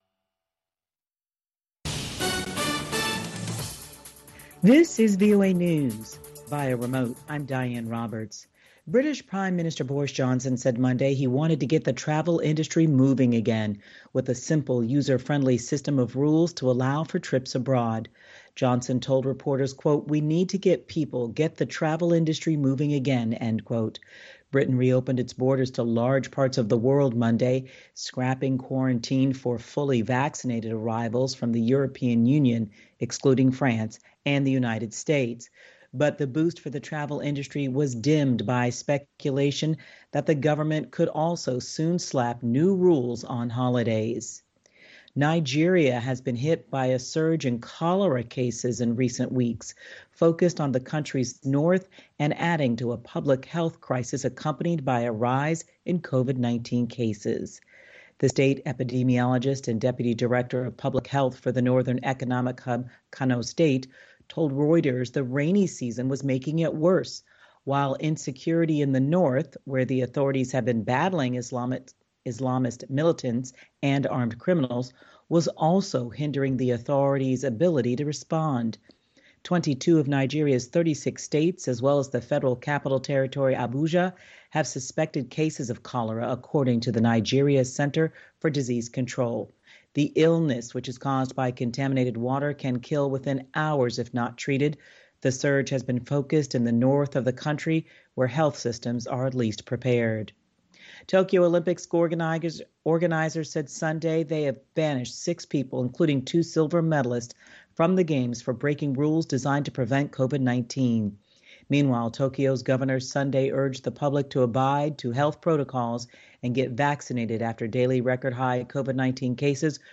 We bring you reports from our correspondents and interviews with newsmakers from across the world.